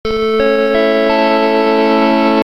Entonces la imagen llevaba implícita otra melodía creada al efecto, confirmando que la cosa estaba chunga mientras un escalofrío recorría tu espalda.
Macintosh IIfx. Casi idéntico aunque más espaciado.
Macintosh-IIfx-Death.m4a